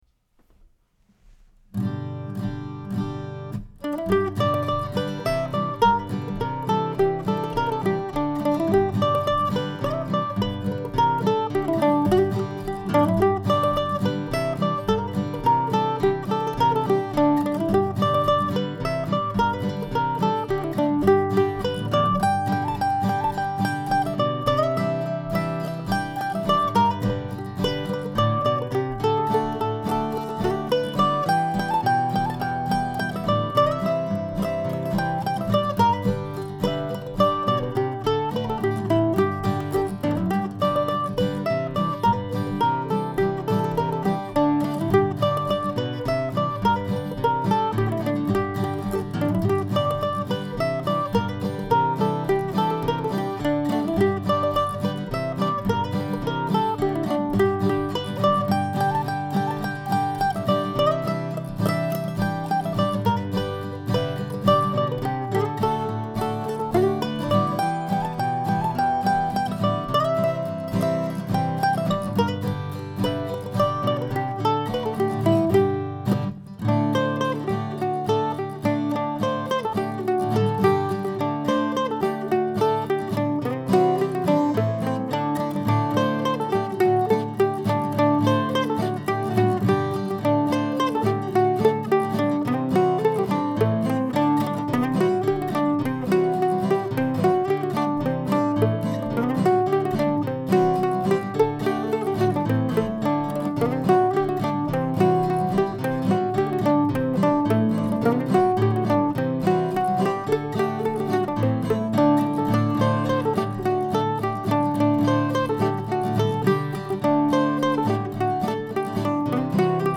It also has me making a few mistakes in the repeat of the first A section.
It's played faster here and I've changed the melody to make that easier to do.